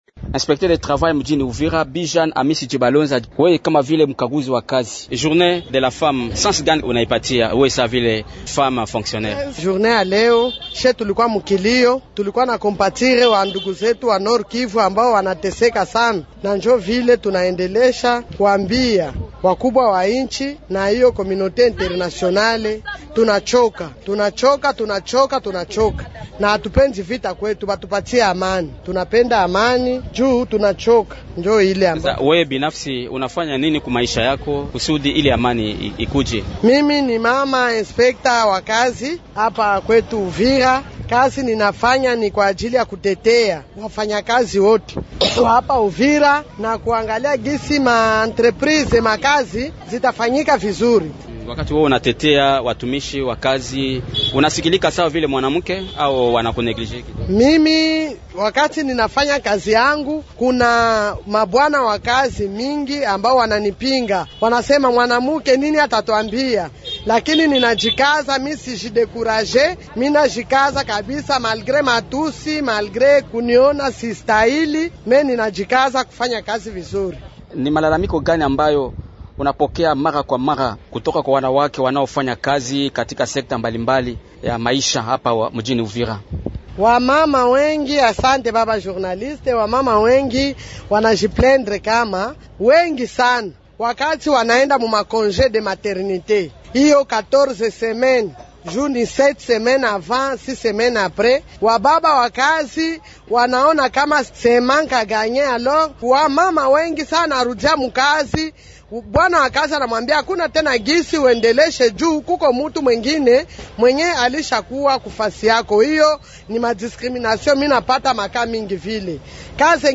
Kama sehemu ya Mwezi wa Wanawake, anatetea kuheshimiwa kwa haki za wanawake wanaofanya kazi na manufaa yanayohusiana na mkataba wao wa kazi. Yeye ndiye mgeni wetu